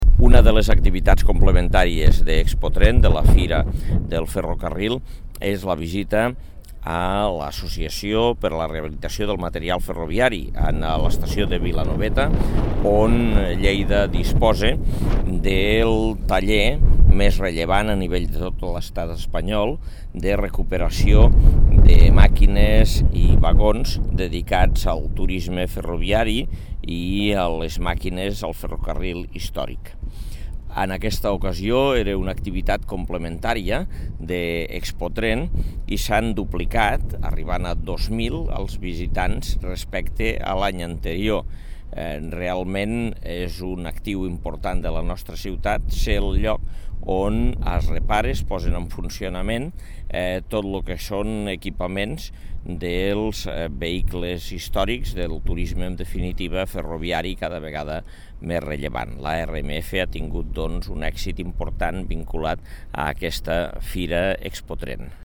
Tall de veu de l'alcalde, Àngel Ros, sobre l'èxit de les visites a l'ARMF, Associació per a la Reconstrucció i Posta en Servei de Material Ferroviari Històric (1019.6 KB) Fotografia 1 amb major resolució (2.8 MB) Fotografia 2 amb major resolució (2.2 MB)